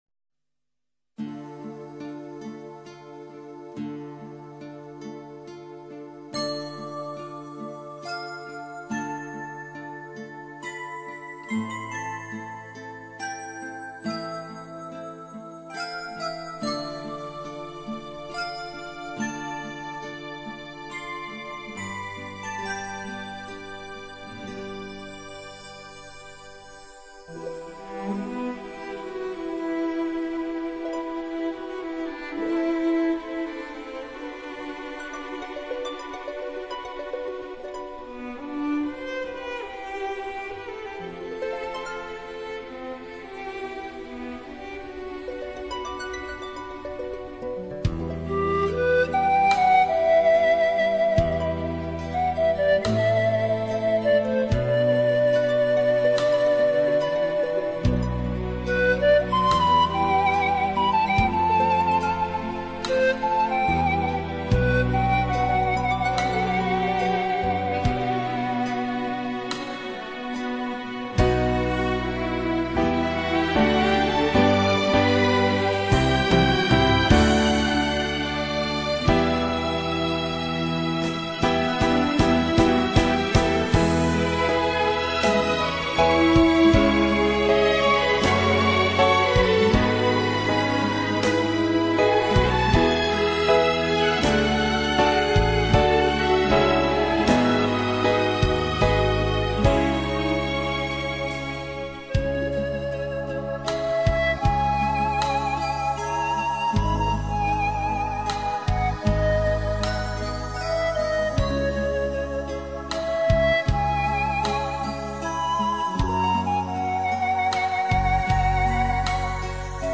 情调音乐专辑
优美难忘的世界电影音乐主题旋律